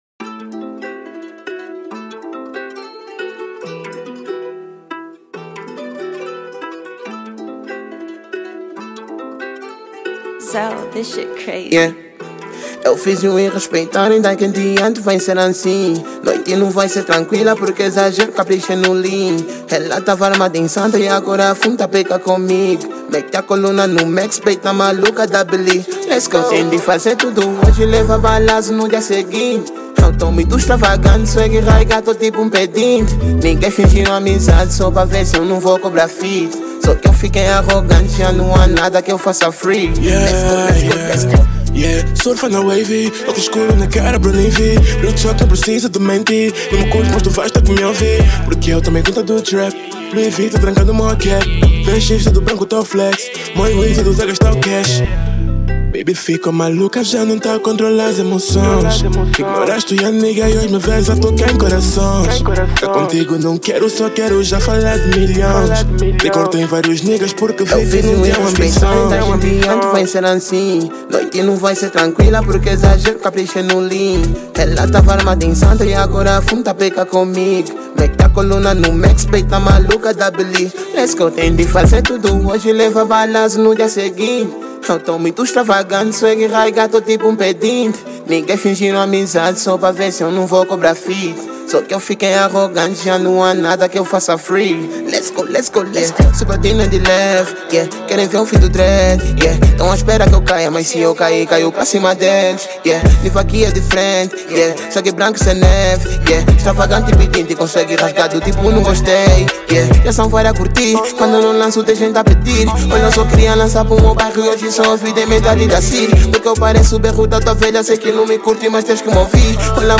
cantada em um beat Drill